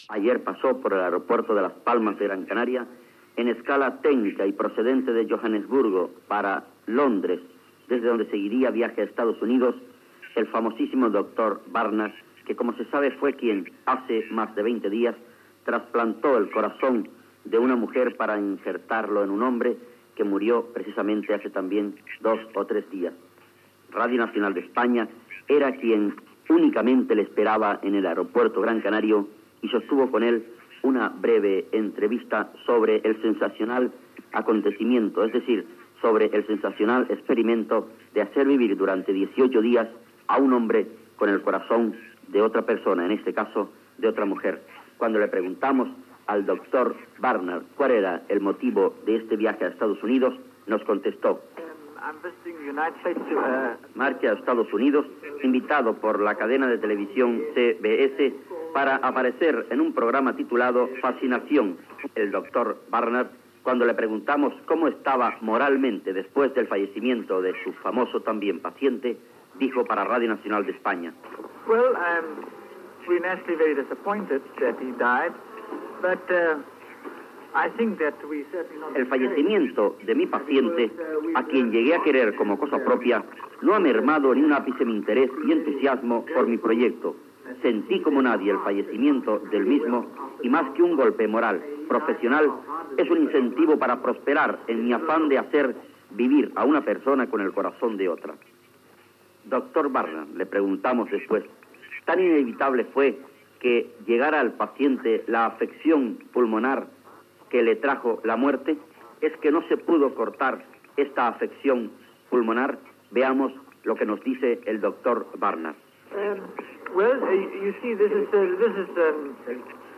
Entrevista al doctor Christiaan Barnard, que el dia 3 de desembre havia fet el primer trasplantament de cor en un hospital de Sud-àfrica, a l'aeroport de Gran Canàries on l'avió havia fet escala el dia abans
Informatiu